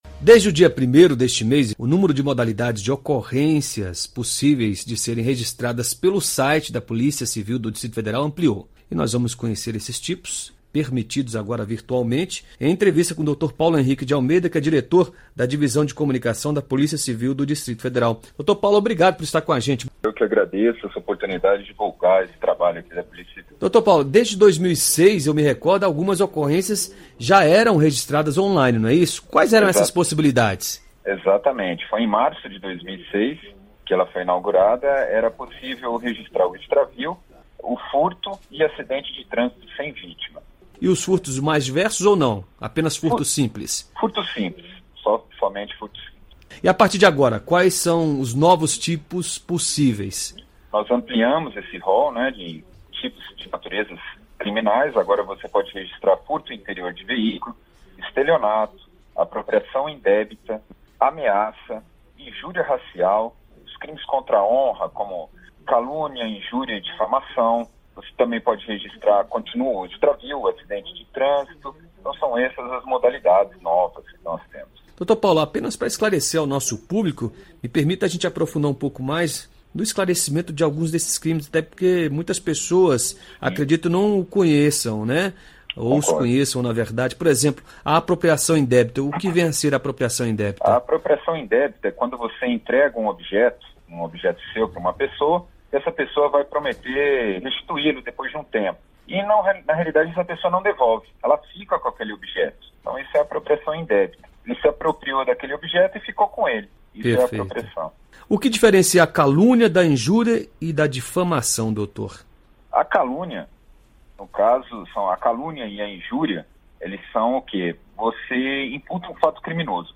Entrevista: Saiba como registrar crimes pela internet no Distrito Federal